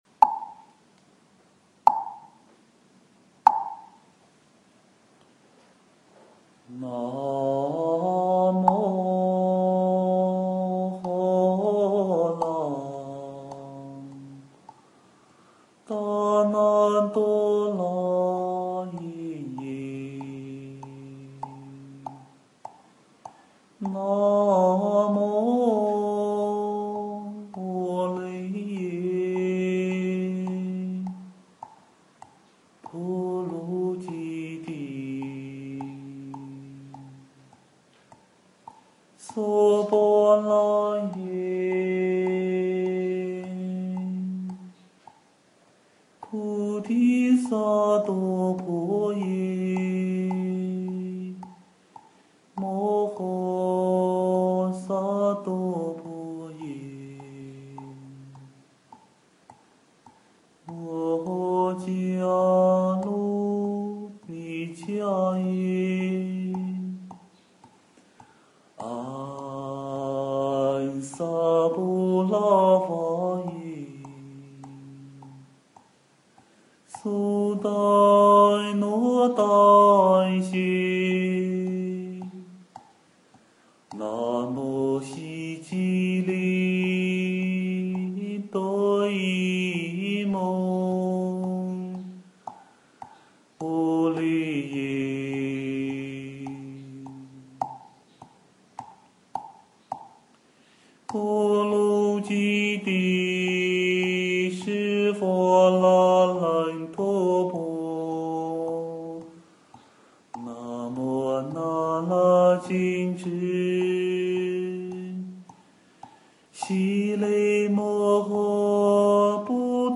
诵经
佛音 诵经 佛教音乐 返回列表 上一篇： 大悲咒 下一篇： 大悲咒-念诵 相关文章 来自大海的声音--未知 来自大海的声音--未知...